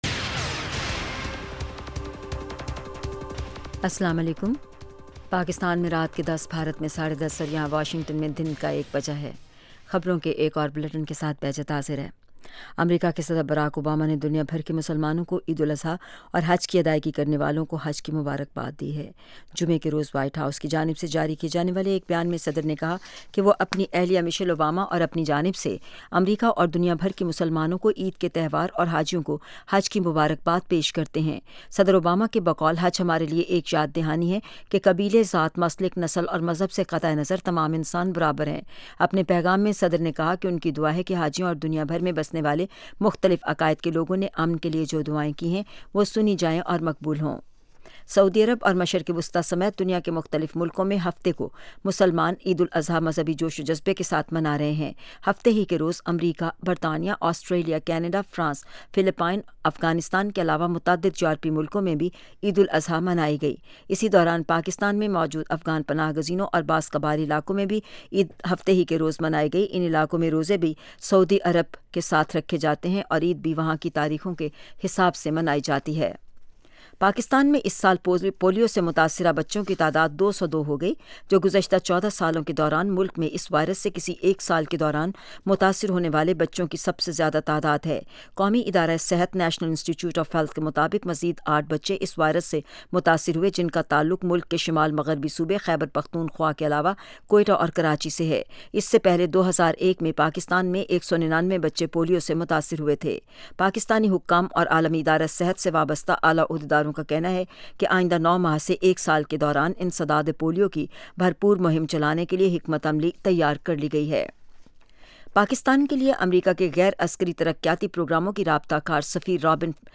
اس پروگرام میں تجزیہ کار اور ماہرین سیاسی، معاشی، سماجی، ثقافتی، ادبی اور دوسرے موضوعات پر تفصیل سے روشنی ڈالتے ہیں۔